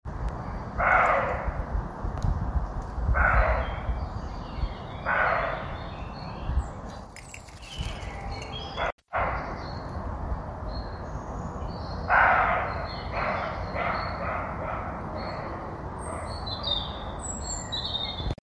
Aboie
Aboiements :
Chevreuil d'Europe, Aboiement I
ChevreuildEurope-Aboiements.mp3